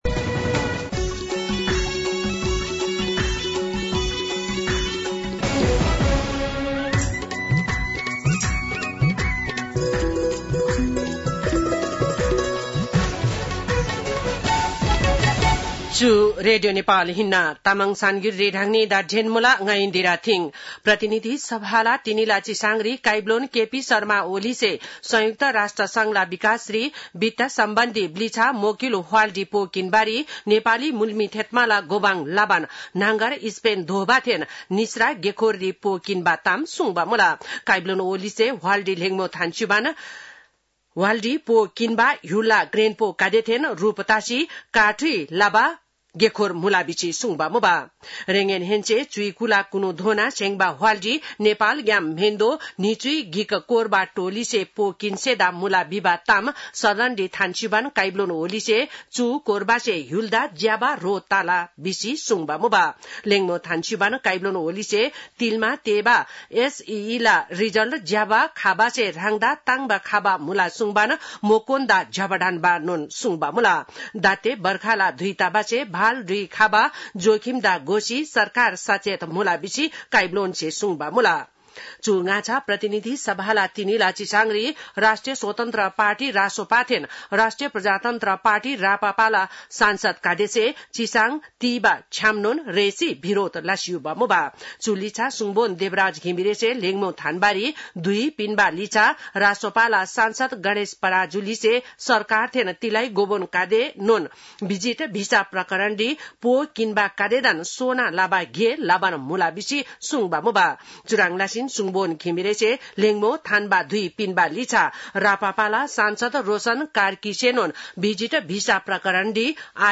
तामाङ भाषाको समाचार : १४ असार , २०८२
5.5-pm-tamang-news-.mp3